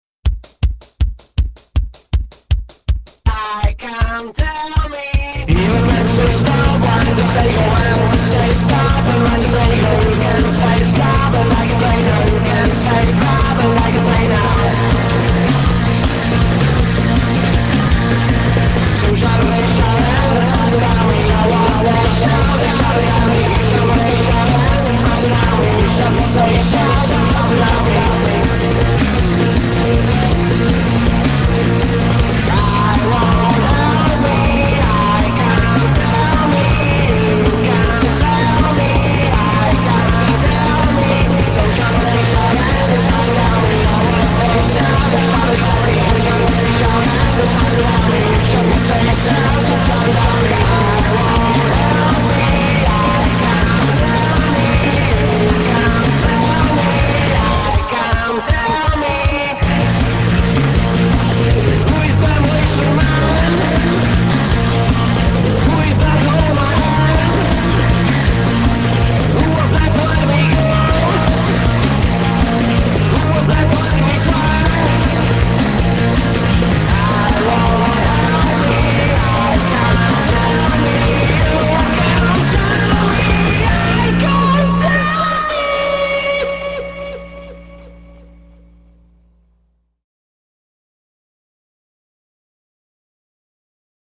184 kB MONO